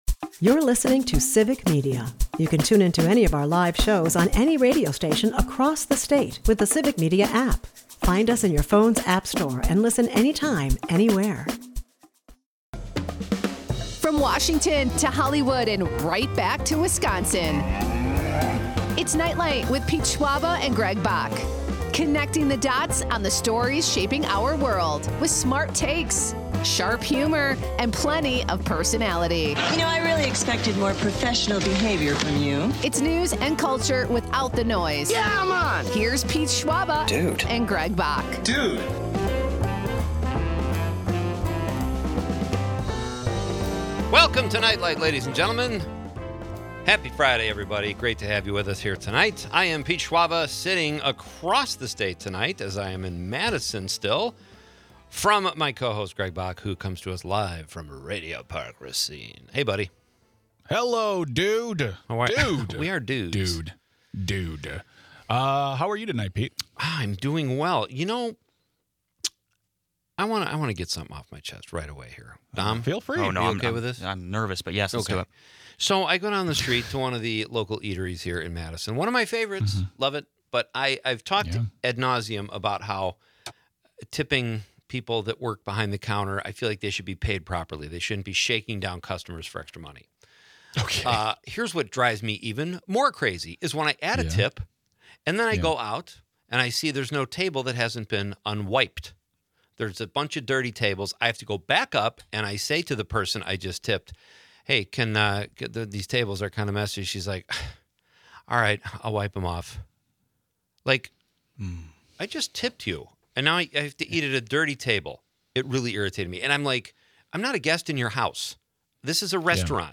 Live in Studio